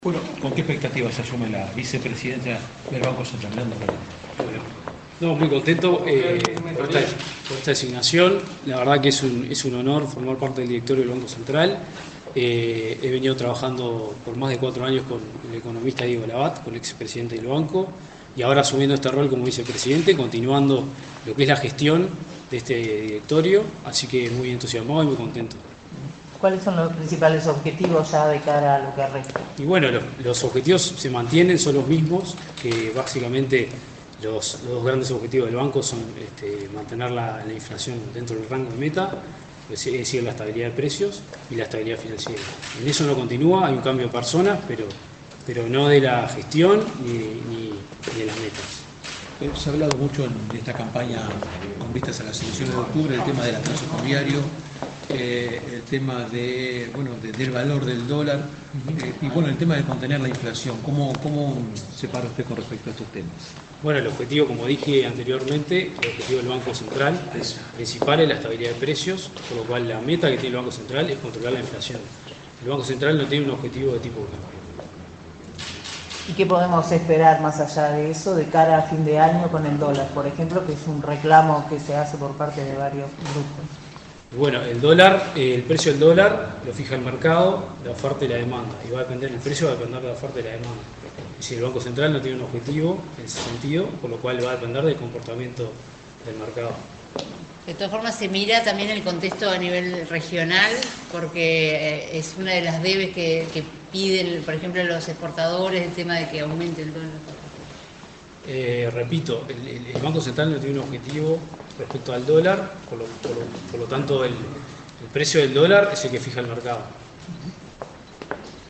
Declaraciones del nuevo vicepresidente del directorio del BCU, Martín Inthamoussu 27/08/2024 Compartir Facebook X Copiar enlace WhatsApp LinkedIn El nuevo vicepresidente del Banco Central del Uruguay (BCU), Martín Inthamoussu, dialogó con la prensa, durante el acto en el que asumió su cargo en el directorio del organismo.